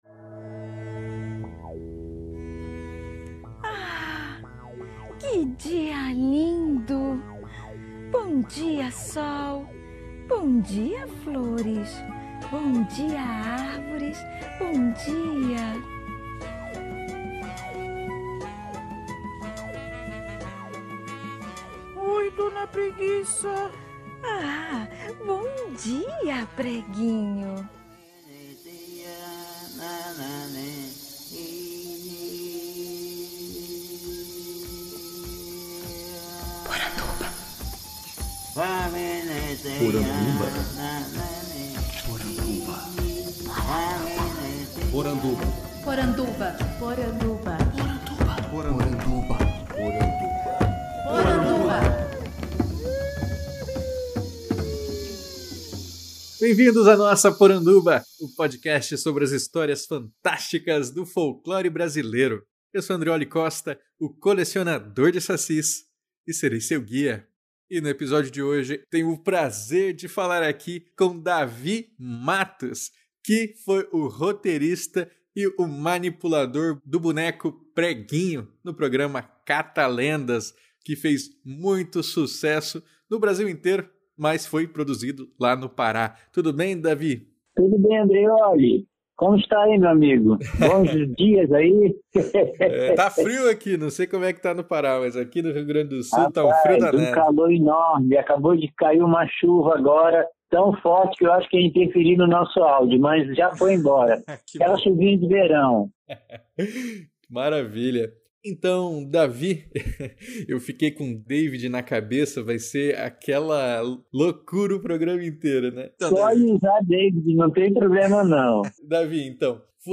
– Canto de abertura e encerramento do povo Ashaninka .